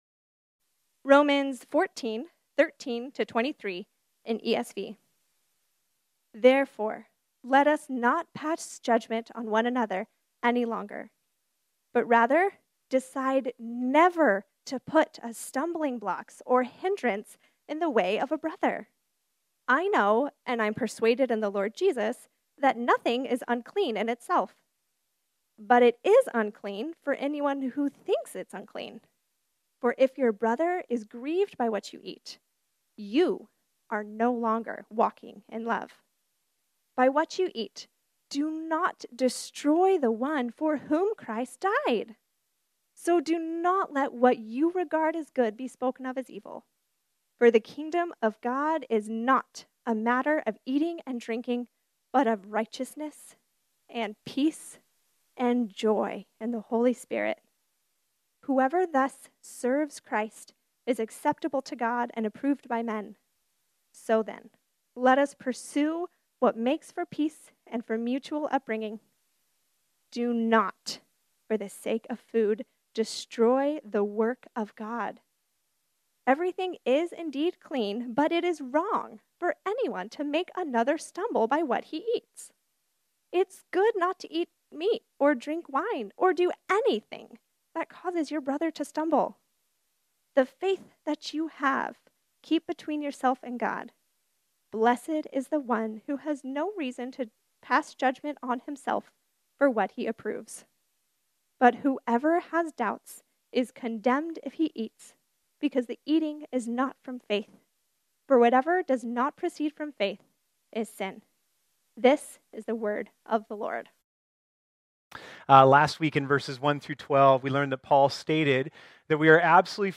This sermon was originally preached on Sunday, August 8, 2021.